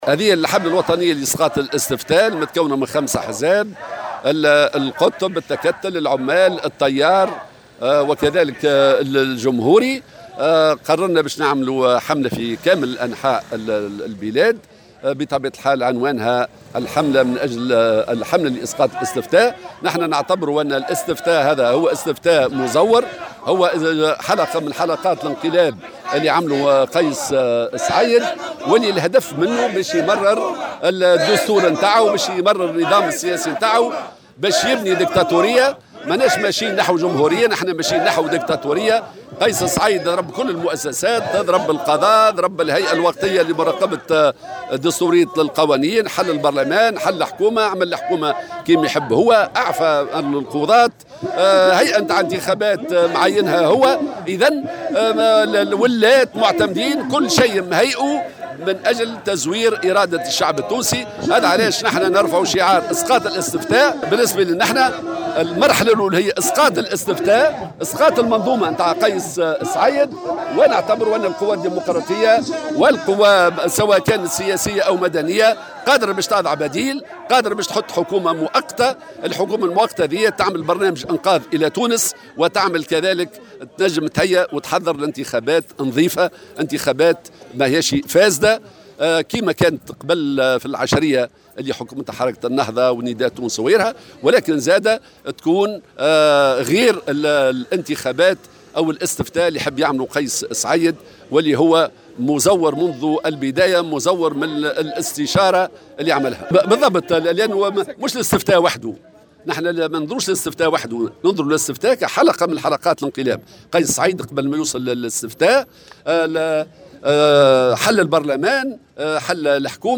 نفذت تنسيقية الاحزاب الاجتماعية الديمقراطية (حزب العمال، التيار الديمقراطي، التكتل، حزب القطب)، اليوم الأحد، مسيرة انطلقت من امام سوق الصناعات التقليدية بنابل وجابت شوارع المدينة وصولا الى مقر الهيئة الفرعية المستقلة للانتخابات بنابل، وذلك في إطار "حملة إسقاط الاستفتاء".